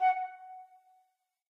flute.ogg